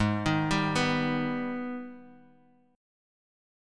AbmM7 chord